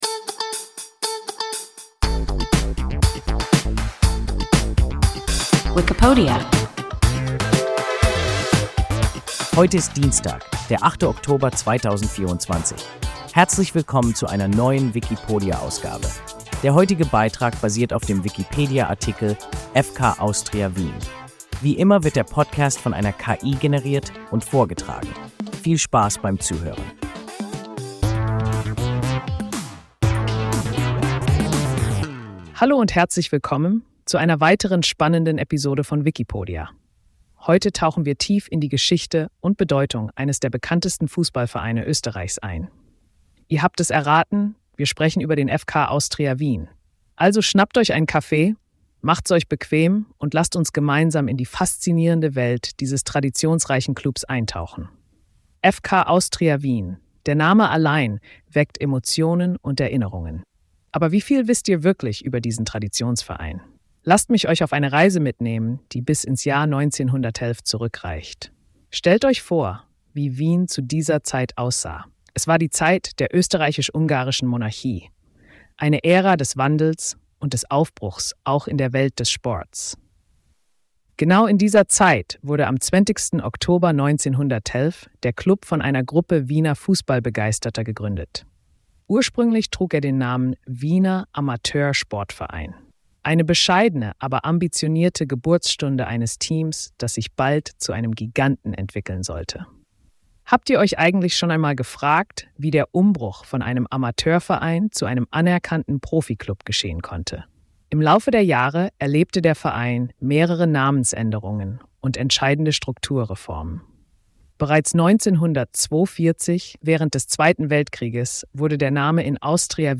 FK Austria Wien – WIKIPODIA – ein KI Podcast